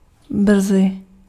Ääntäminen
IPA : /suːn/ US : IPA : [suːn]